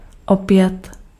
Ääntäminen
IPA: [aŋˈkoː.ra]